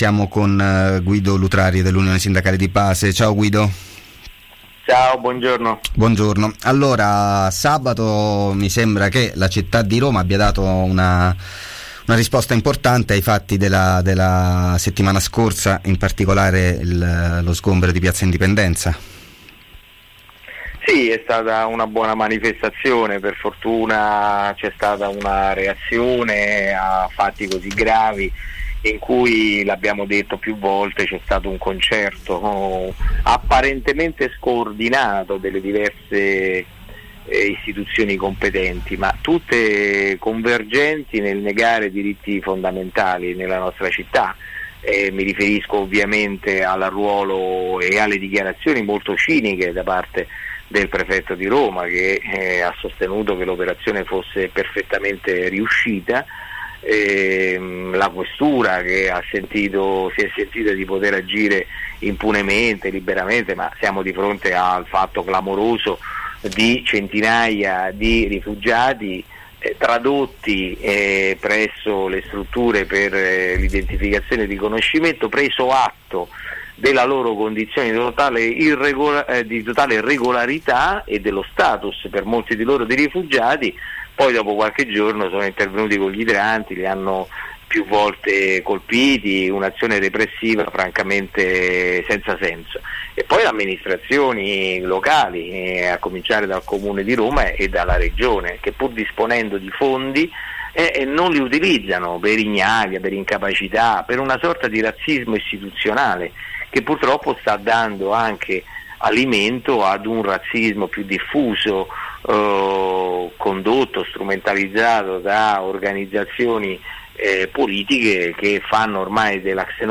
Sabato a Roma il corteo contro gli sgomberi: intervista